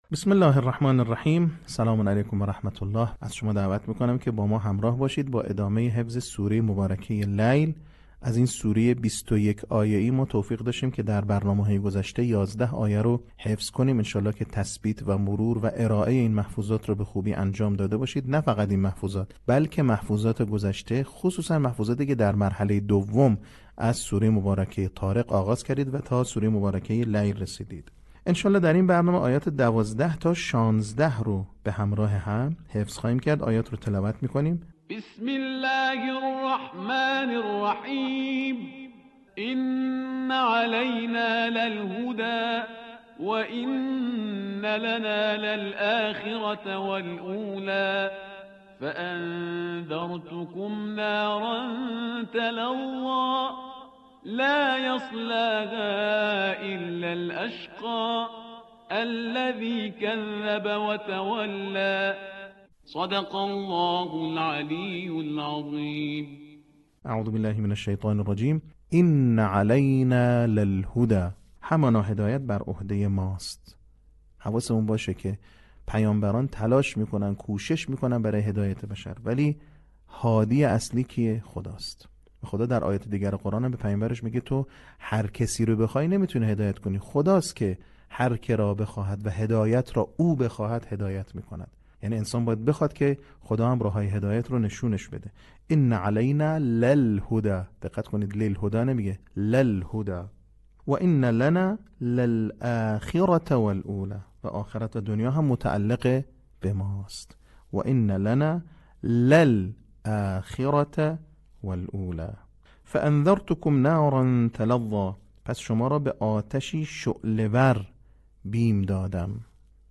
صوت | بخش سوم آموزش حفظ سوره لیل